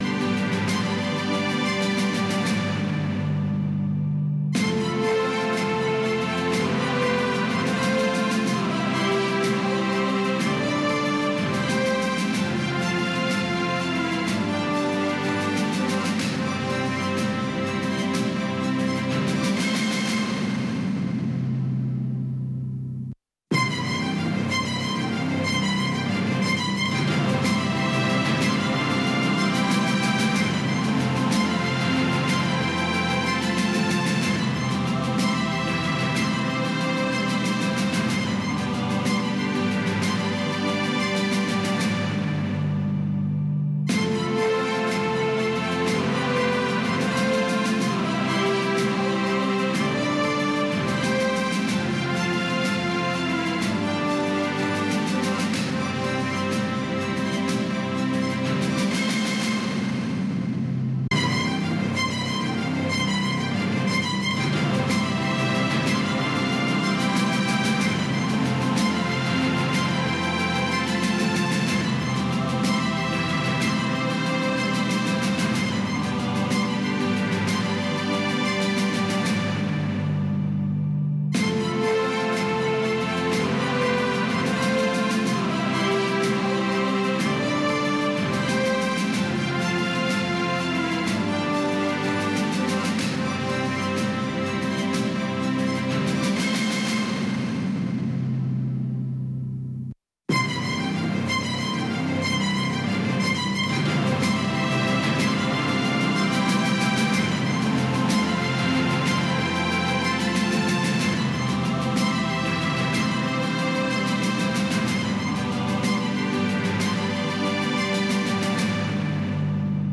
خبرونه